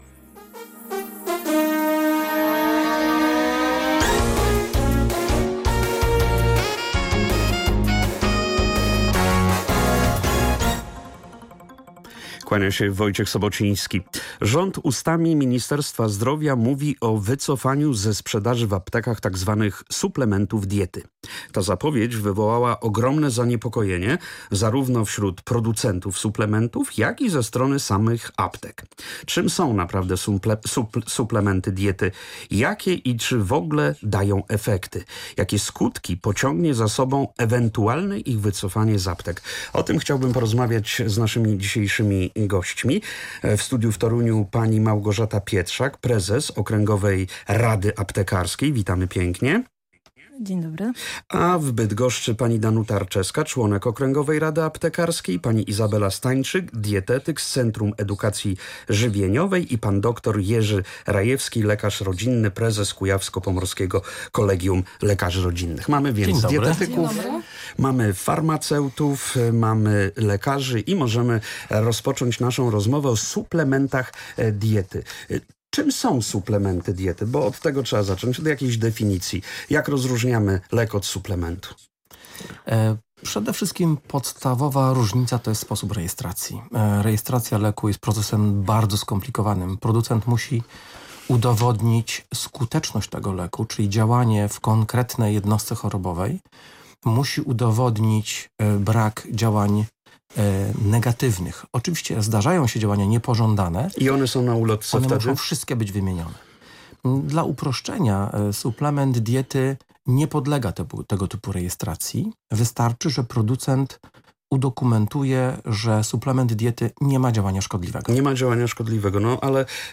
audycja radiowa